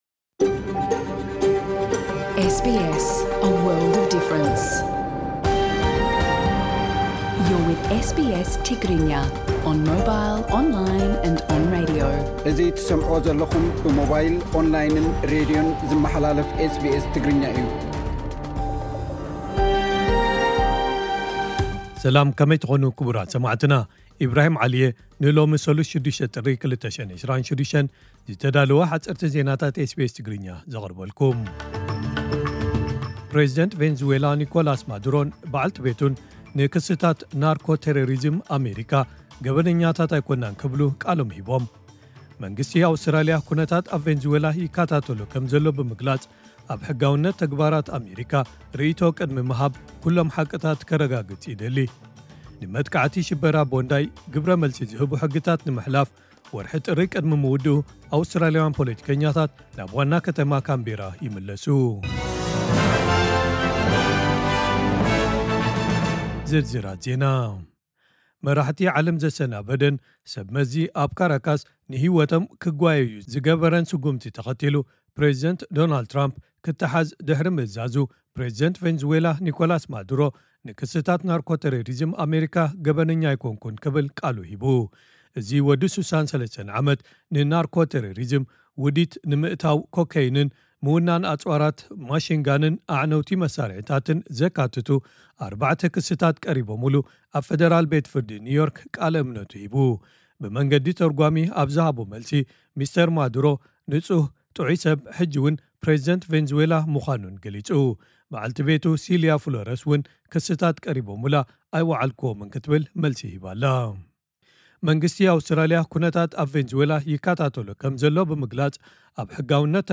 ዕለታዊ ዜና ኤስቢኤስ ትግርኛ (06 ጥሪ 2026)